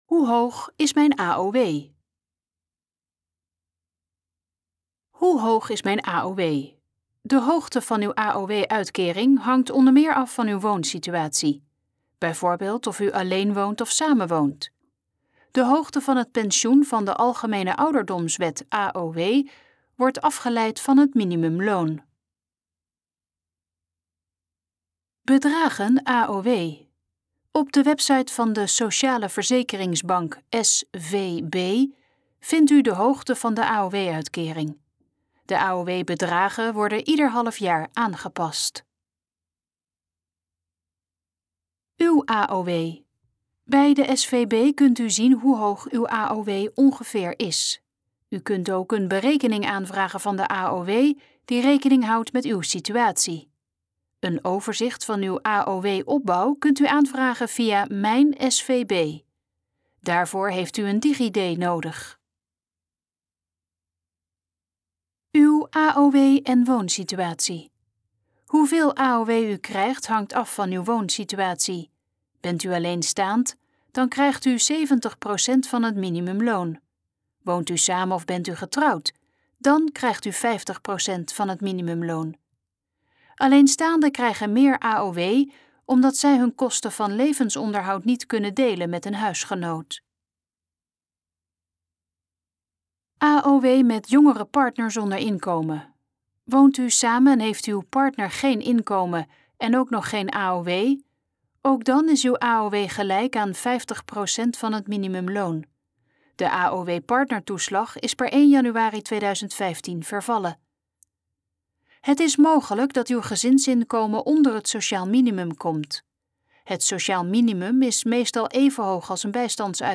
Dit geluidsfragment is de gesproken versie van de pagina Hoe hoog is mijn AOW?